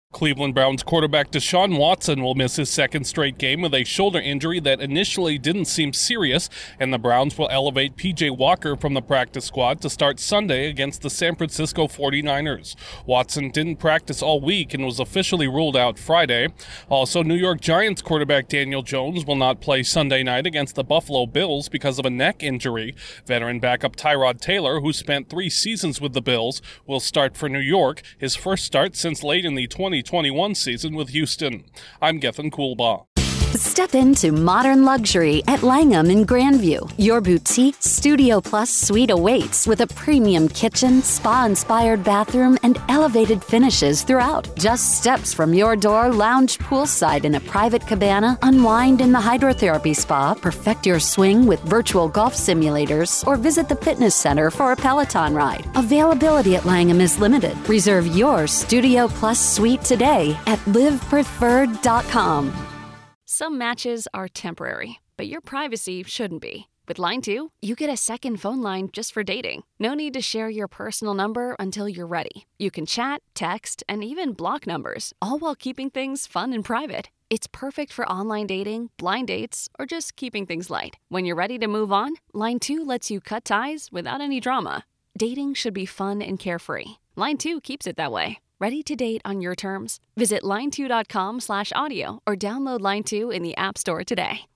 The Browns and Giants will be without their starting quarterbacks this weekend. Correspondent